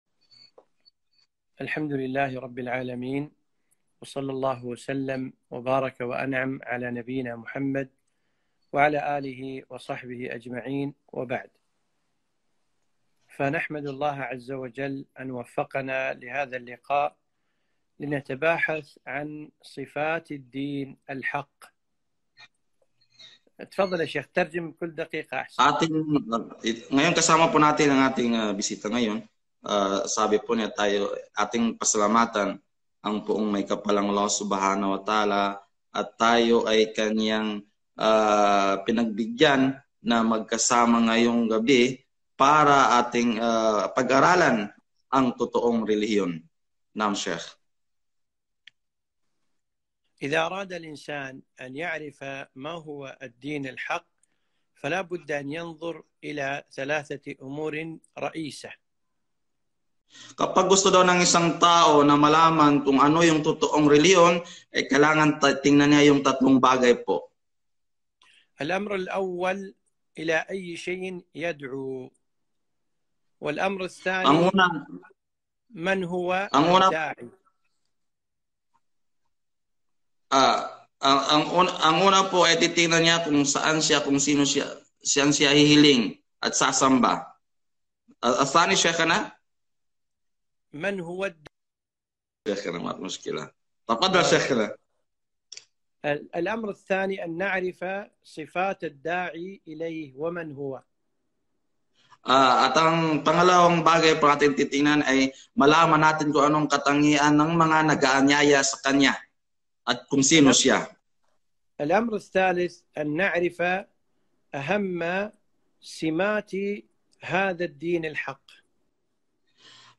محاضرة للجالية الفلبينية بعنوان - علامات الدين الحق